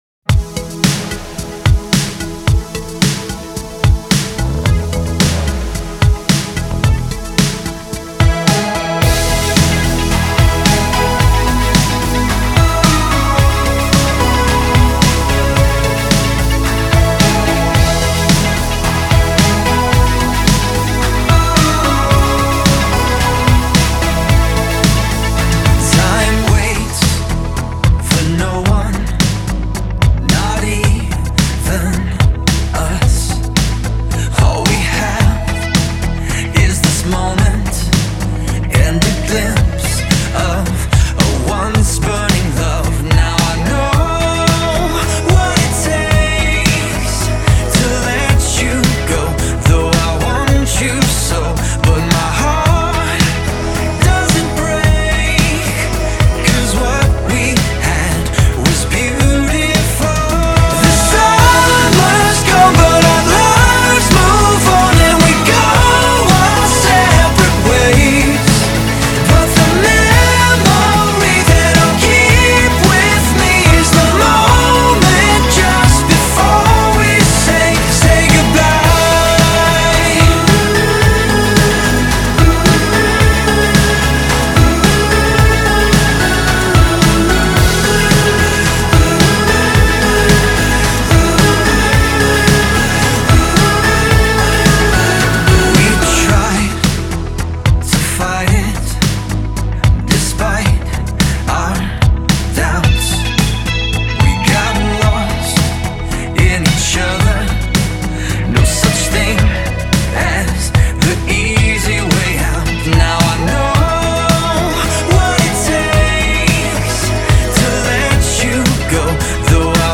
synth–pop duo
they also embody the ambient vibes of chillwave.